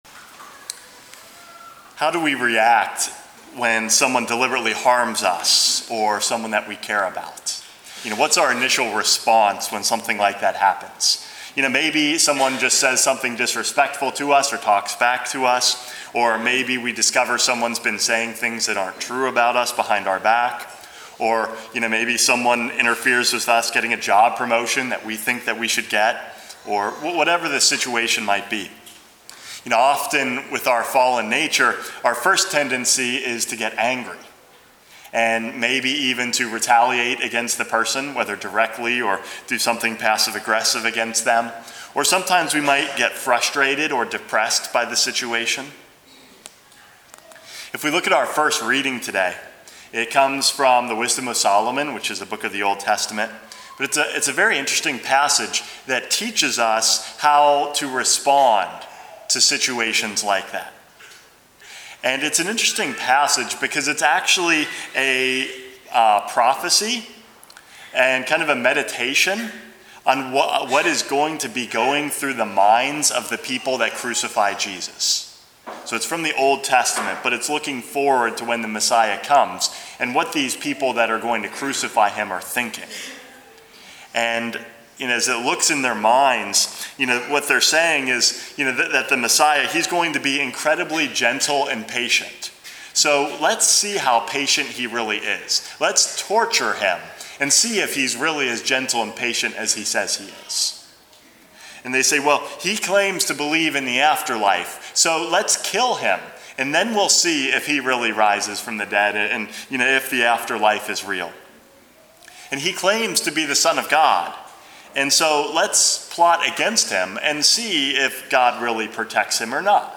Homily #415 - Facing Trials